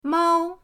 mao1.mp3